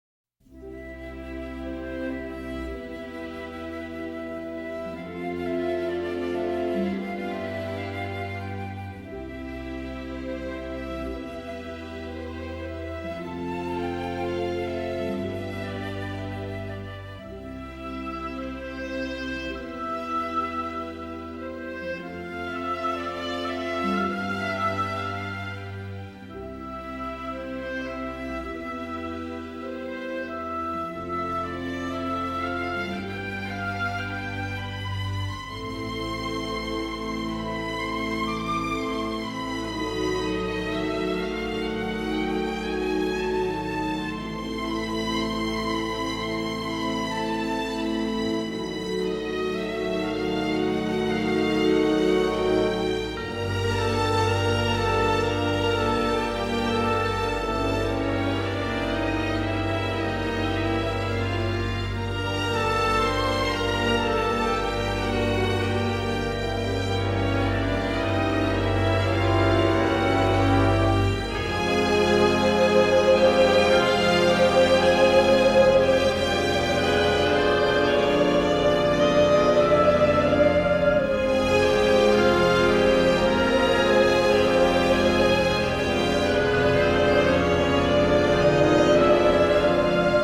una vigorosa música